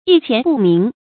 一錢不名 注音： ㄧ ㄑㄧㄢˊ ㄅㄨˋ ㄇㄧㄥˊ 讀音讀法： 意思解釋： 謂身無分文。